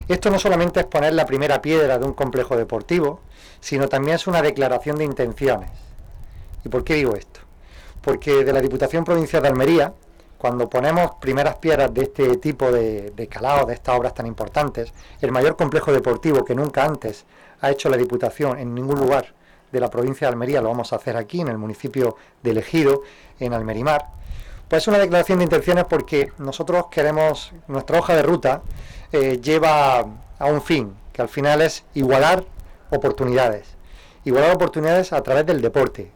El alcalde de El Ejido, Francisco Góngora, y el Presidente de la Diputación Provincial de Almería, Javier Aureliano García, han llevado a cabo hoy el acto de colocación de la primera piedra del Complejo Deportivo Almerimar, acompañados de las corporaciones Municipal y Provincial. Durante el acto se han depositado en una urna objetos y los periódicos del día que, posteriormente, se han enterrado como símbolo del inicio de las obras.
10-12_primera_piedra_pabellon_almerimar___presidente.mp3